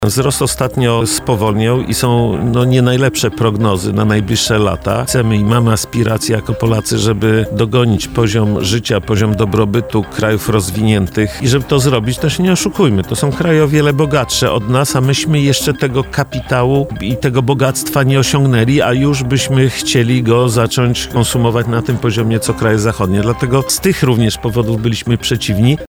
[PORANNA ROZMOWA] Wigilia wolna od pracy to dobry pomysł?